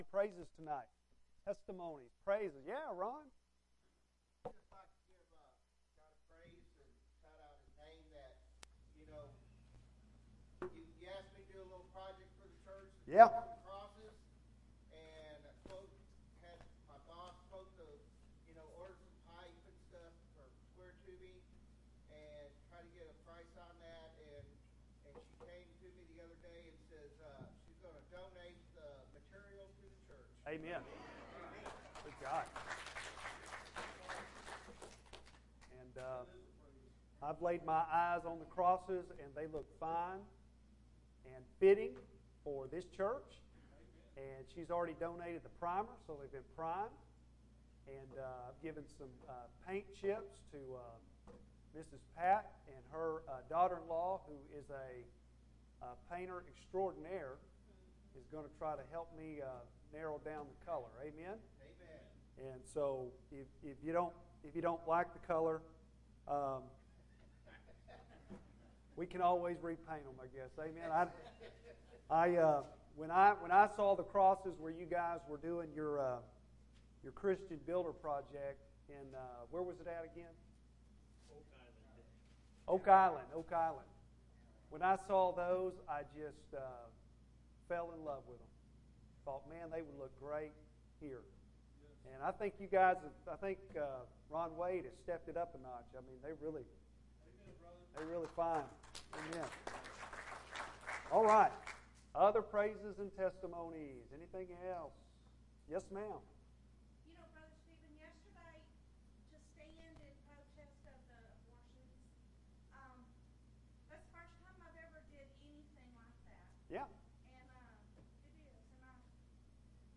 Bible Text: I Corinthians 16:5-12 | Preacher